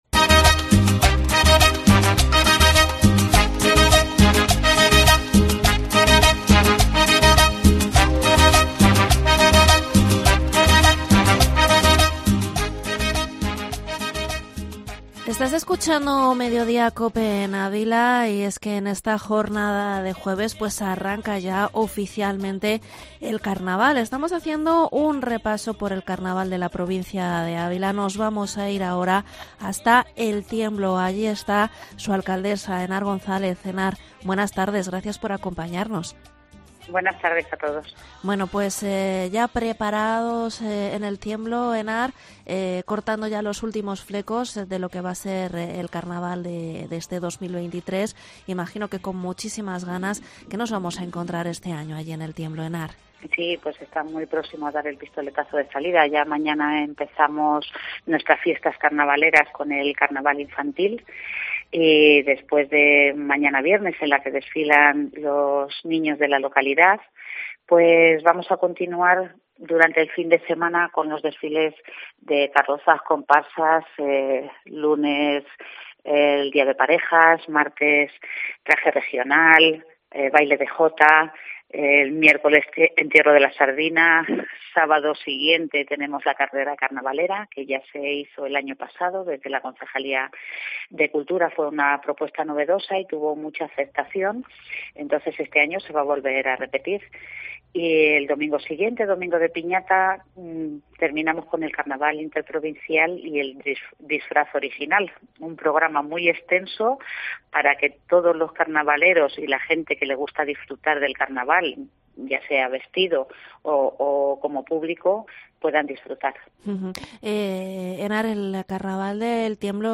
AUDIO: Entrevista alcaldesa de El Tiemblo, Henar González. Carnaval 2023 Foto: Aymto.